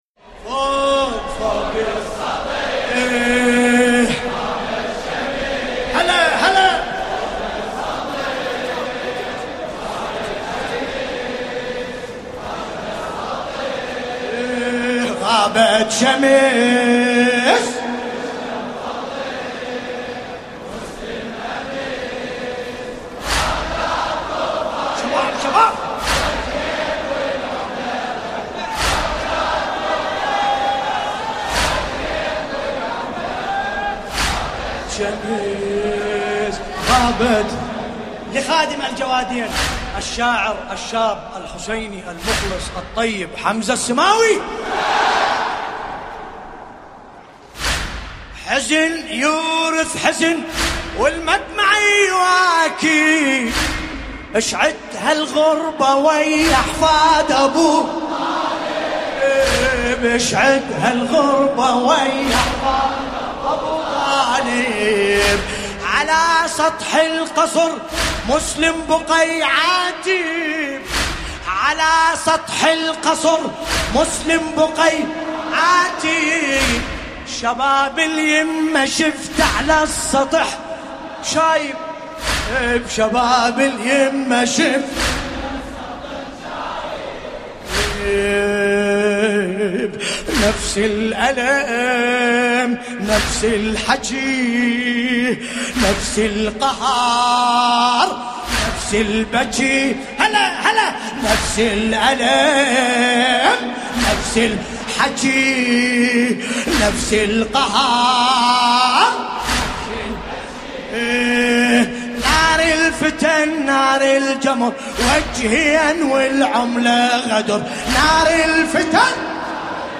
المكان : العتبة الكاظمية المقدسة